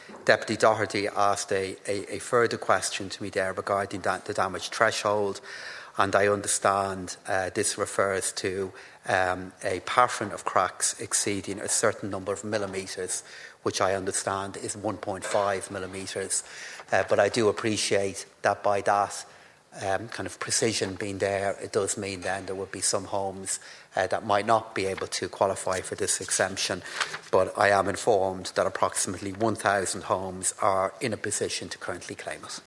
Minister Donohoe, in response to Deputy Doherty, failed to confirm if the damage threshold would be reviewed to allow for LPT exemption to be expanded to include more defective block homeowners: